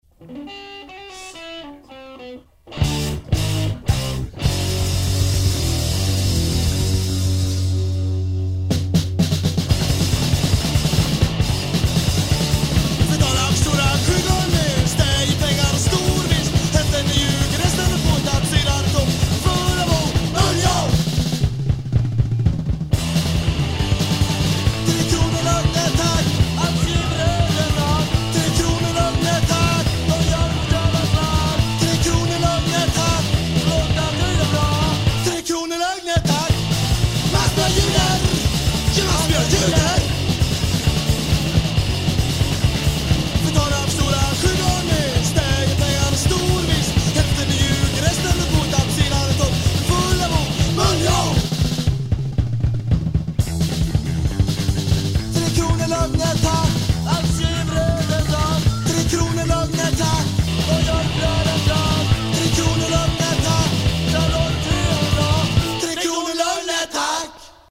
a quartet, who tried to play fast punk
Guitar, voice
Drums